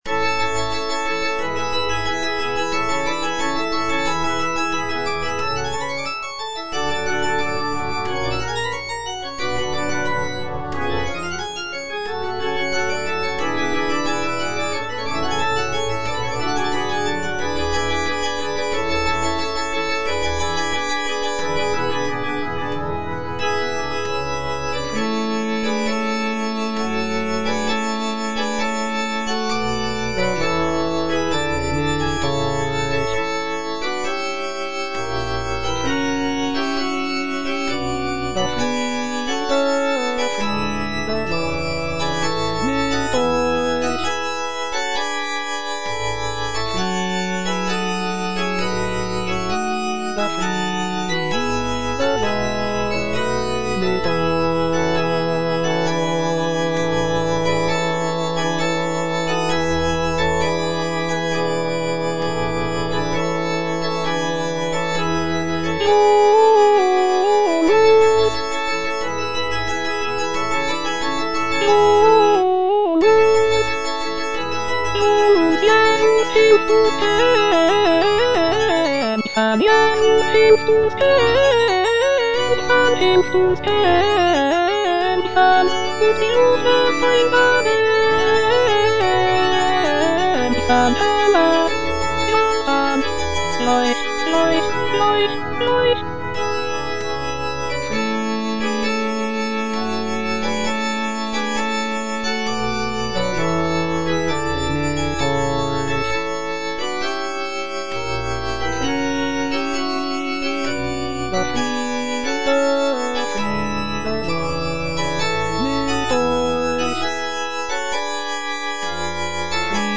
Choralplayer playing Cantata
It features a combination of choruses, arias, and recitatives that reflect on the birth of Jesus Christ and the joy of the Christmas season.